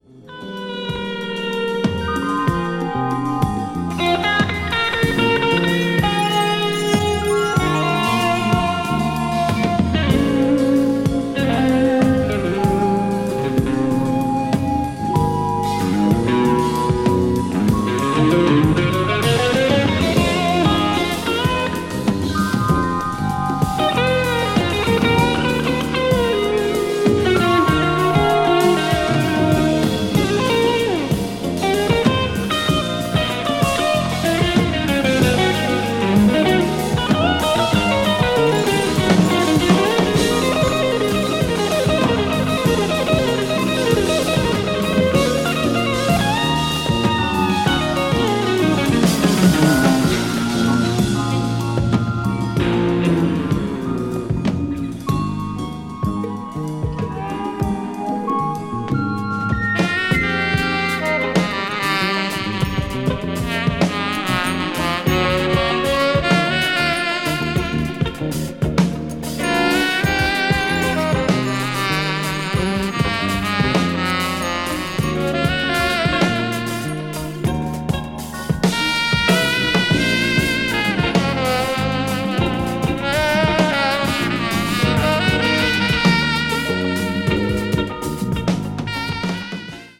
(一部わずかにチリノイズが入る箇所あり)
非常に洗練されたモダンなアレンジによるクロスオーヴァー/フュージョンを全編に披露した名盤です。